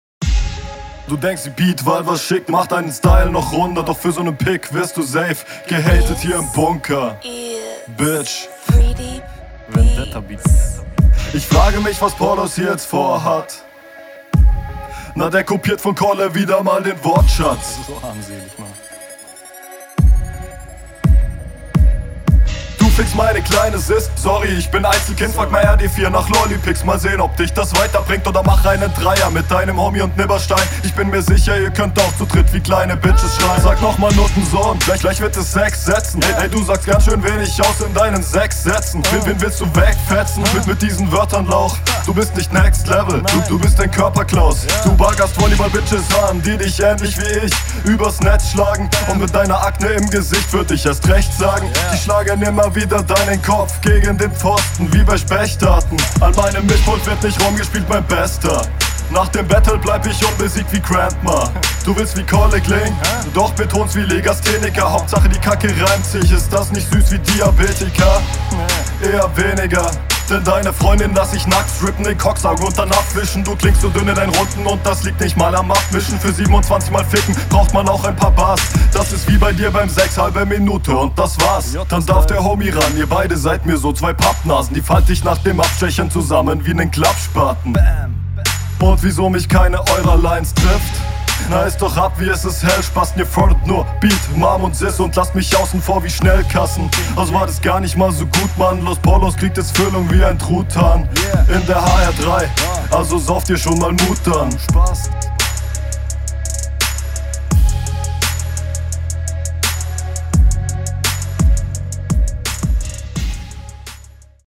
Flow: Ab und an sind hier holperer drinnen.